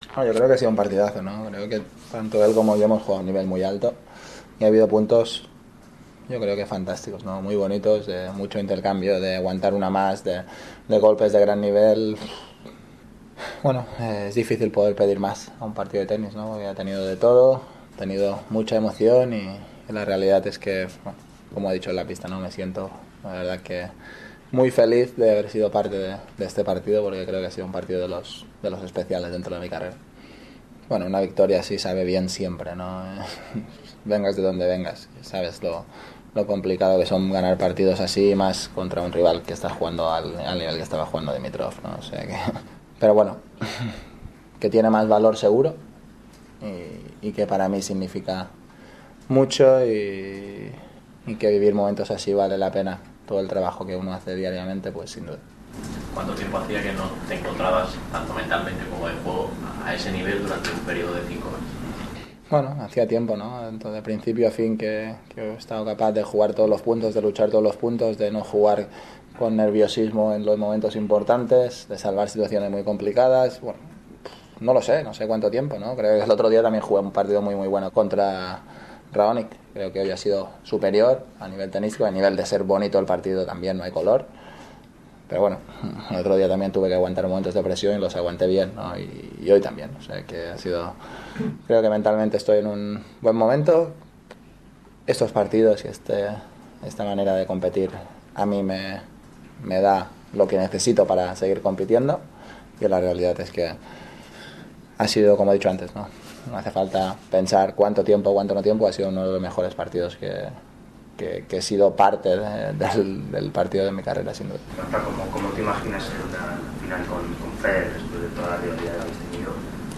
Nadal se enfrenta ahora con su legendario rival, el suizo Roger Federer (17), de quien dice ha evolucionado paralelamente a él, hacia este momento definitivo en el primer Grand Slam de la temporada 2017. Escucha aquí la entrevista en español